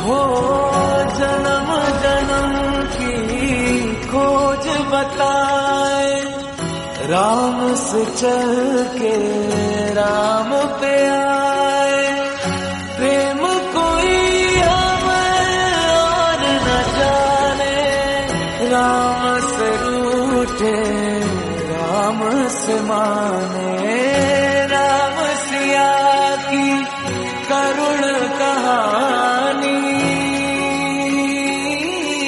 Category Devotional